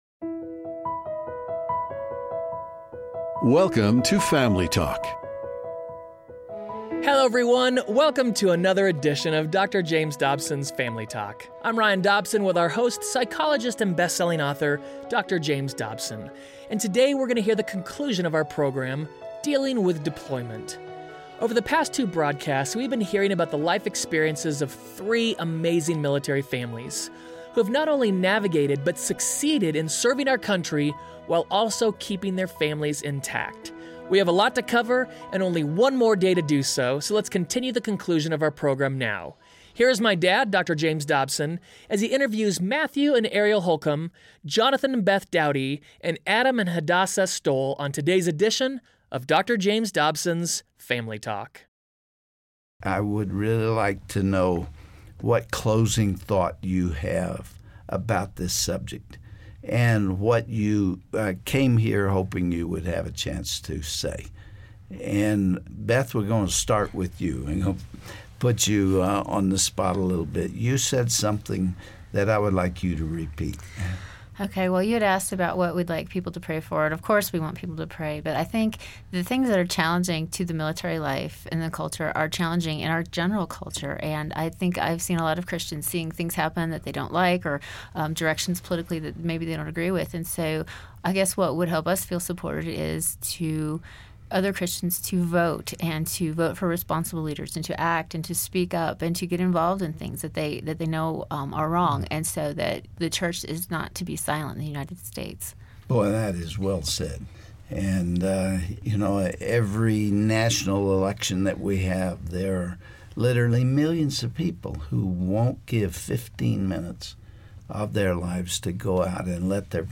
With moving, deployments, and hard experiences, how can marriage and family be done well? Dr. James Dobson concludes his interview with 3 military couples about their stance for faith and family.